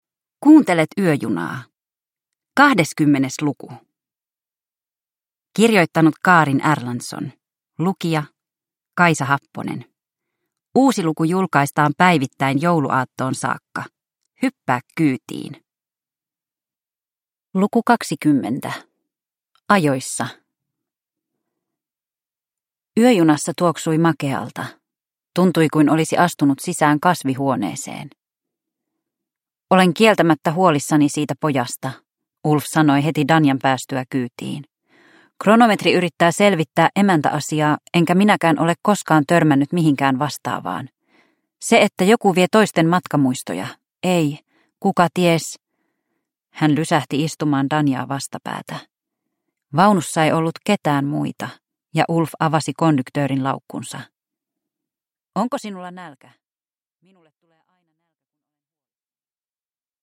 Yöjuna luku 20 – Ljudbok